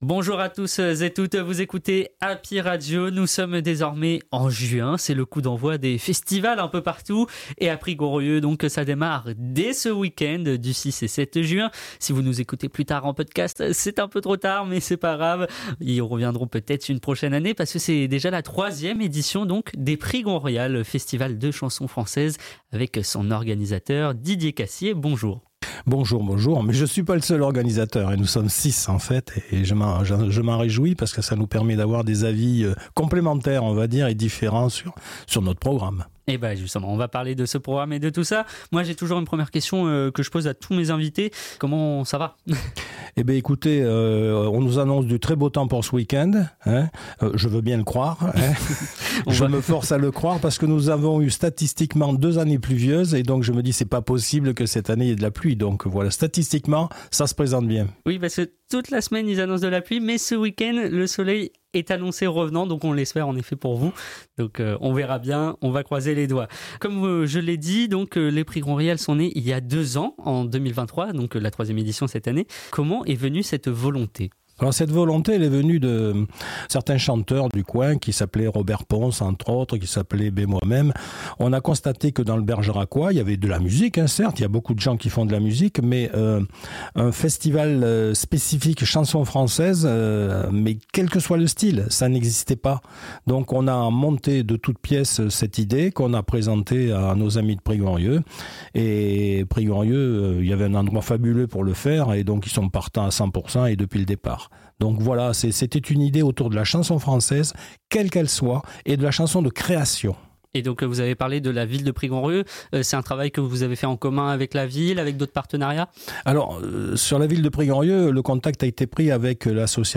LES INTERVIEWS HAPPY RADIO – LES PRIGONRIALES